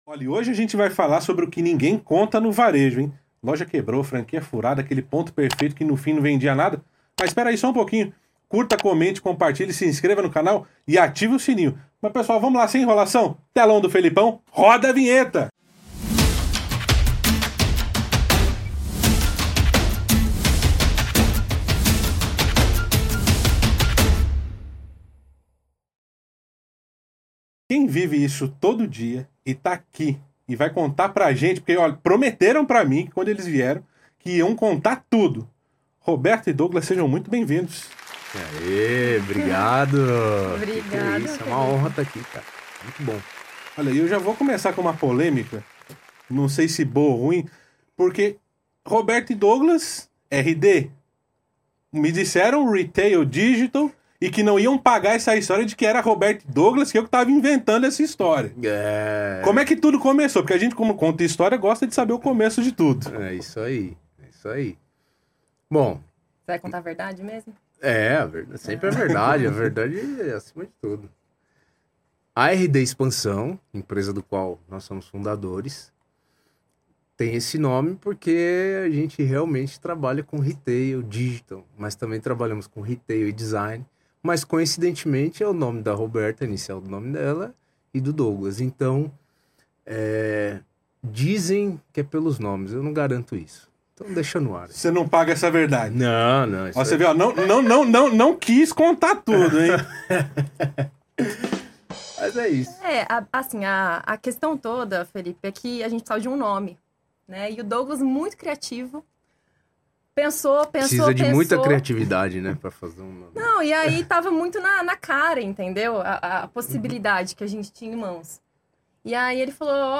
pt Genres: Comedy , Comedy Interviews Contact email